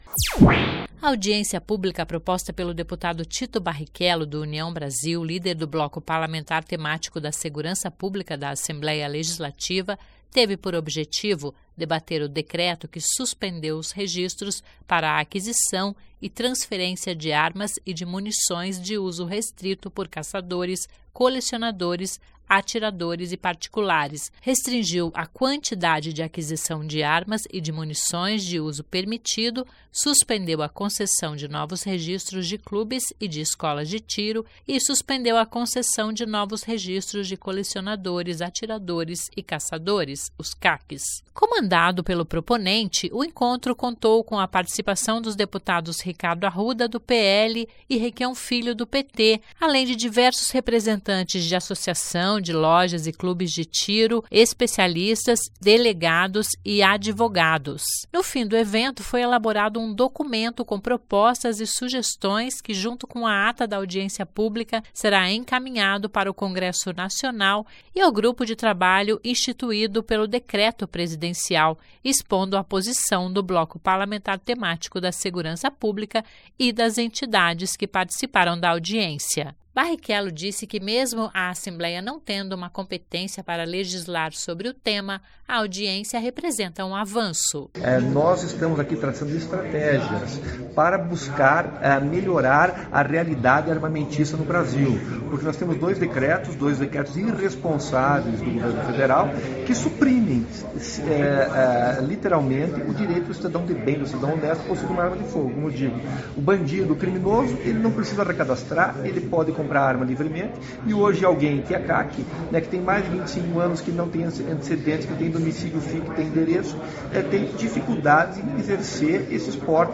O deputado Barichello disse que, mesmo a Assembleia não tendo competência para legal sobre o tema,  a audiência representou um avanço.
O deputado Ricardo Arruda (PL) comentou sobre a audiência e disse ser importante debater a fundo o assunto.
Para o Deputado Requião Filho (PT) a reunião colabora para os debates decorrentes do tema.